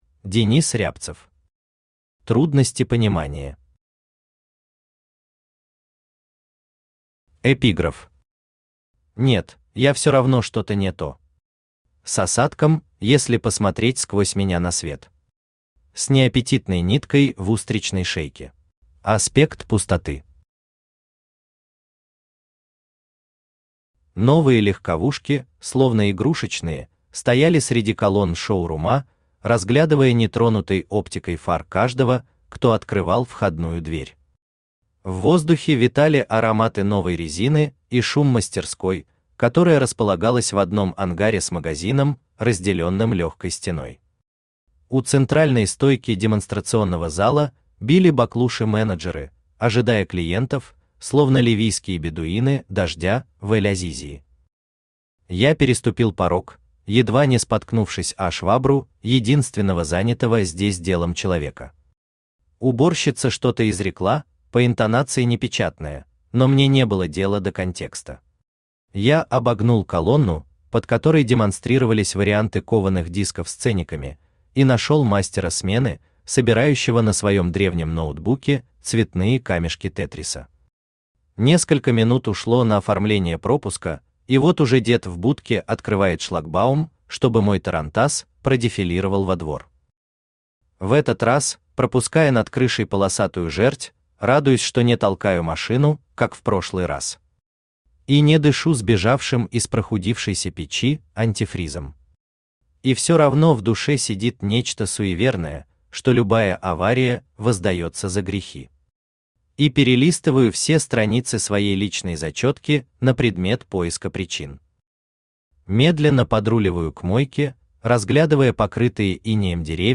Аудиокнига Трудности понимания | Библиотека аудиокниг
Aудиокнига Трудности понимания Автор Денис Евгеньевич Рябцев Читает аудиокнигу Авточтец ЛитРес.